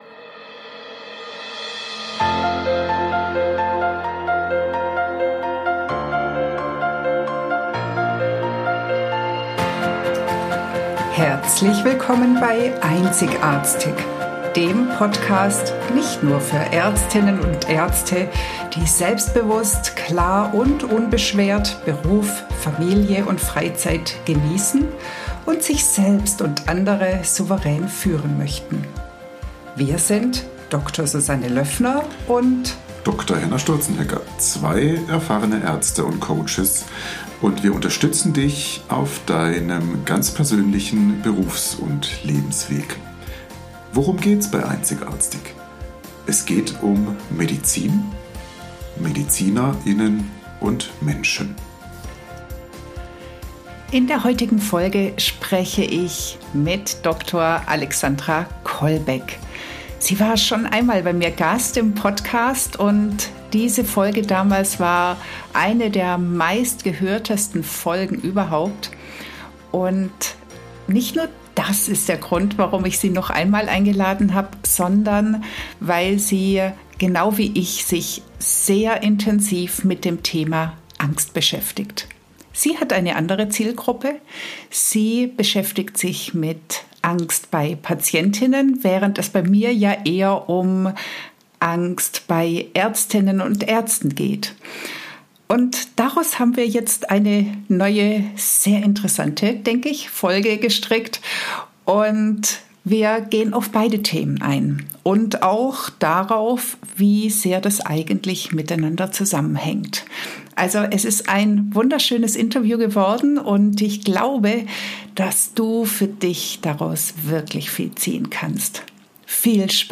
Ich habe wieder mal ein traumhaftes Interview geführt.